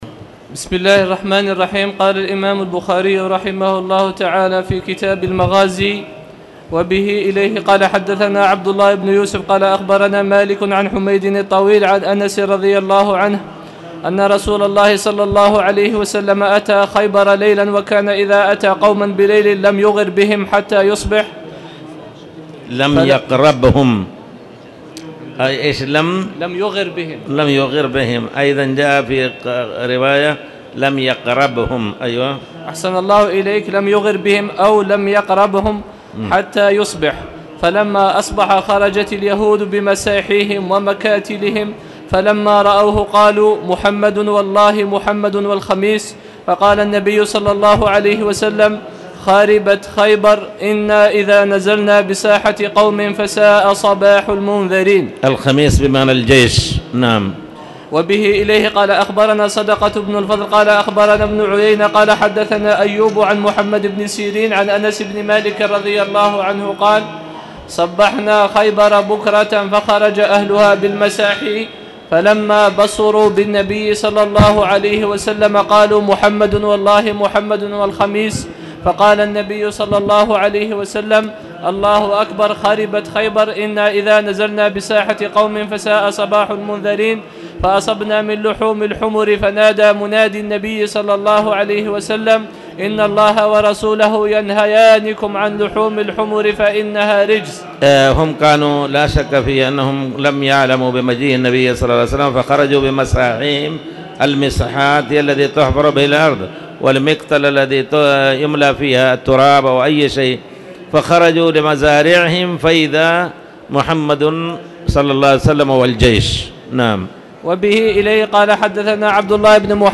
تاريخ النشر ١٨ شوال ١٤٣٧ هـ المكان: المسجد الحرام الشيخ